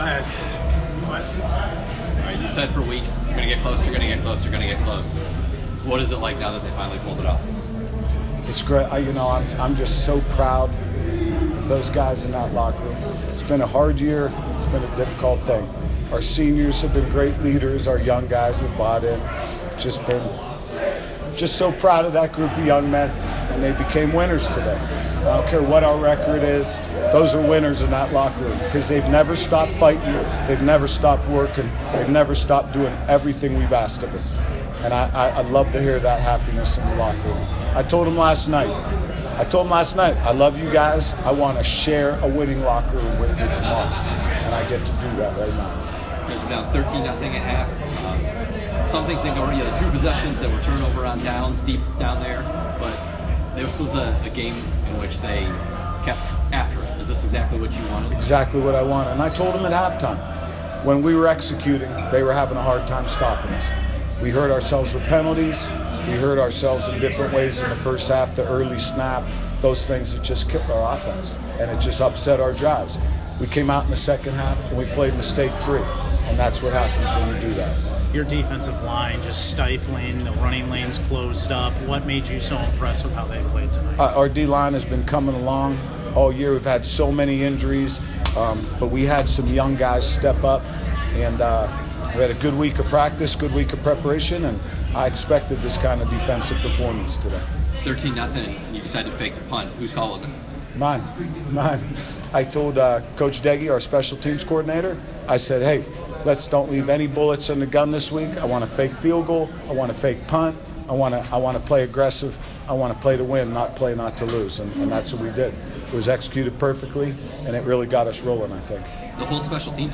Postgame audio (.m4a)